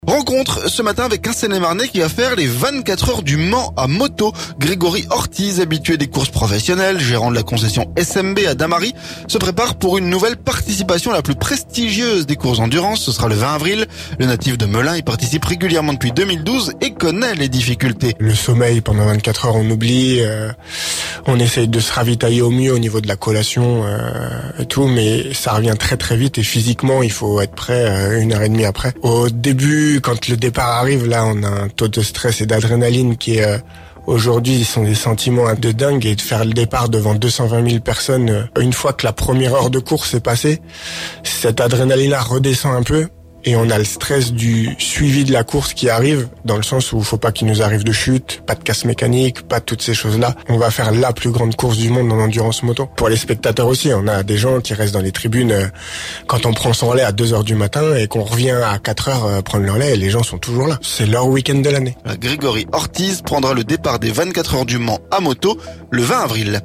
Rencontre avec un Seine-et-marnais qui va faire les 24H du Mans... à Moto !